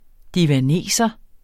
divaneser substantiv, fælleskøn Bøjning -en, -e, -ne Udtale [ divaˈneˀsʌ ] Oprindelse spøgende dannelse af divan med endelsen -eser , i analogi med havanneser , milaneser , vietnameser mfl.